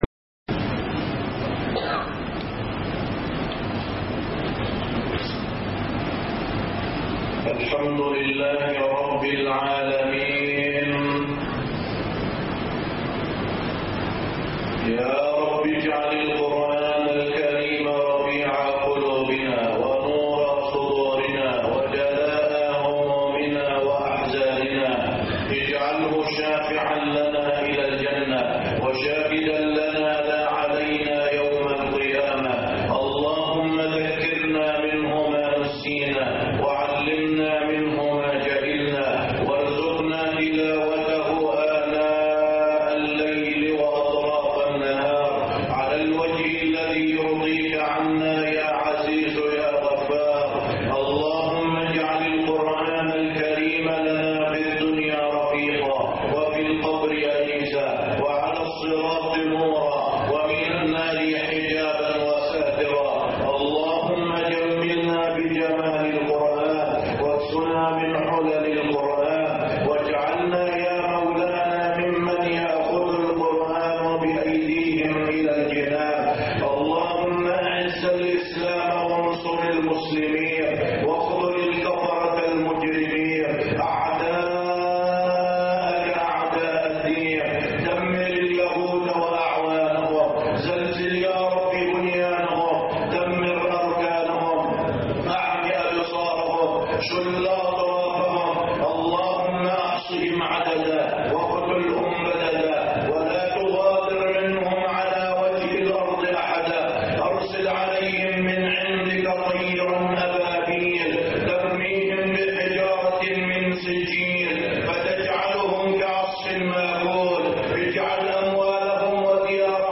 الفريضة المهملة- خطب الجمعة